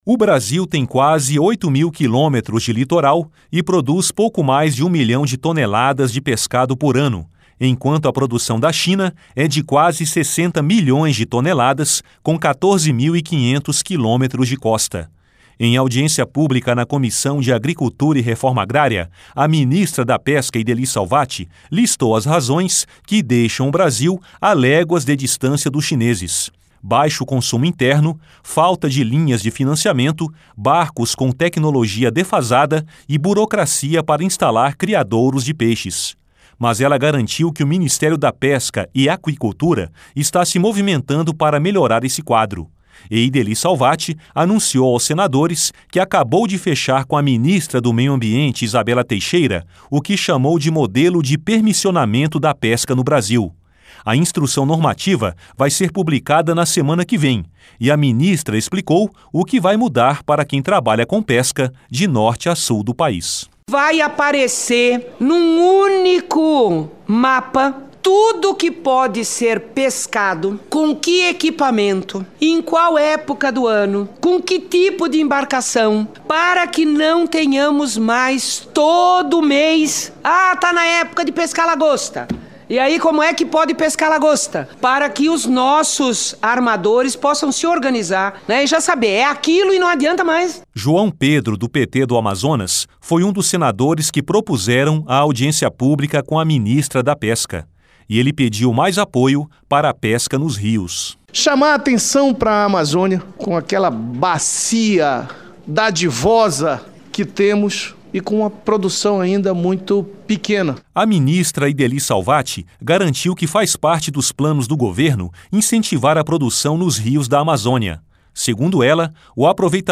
Em audiência pública na Comissão de Agricultura e Reforma Agrária, a ministra da Pesca, Ideli Salvatti, listou as razões que deixam o Brasil a léguas de distância dos chineses: baixo consumo interno, falta de linhas de financiamento, barcos com tecnologia defasada e burocracia para instalar criadouros de peixes.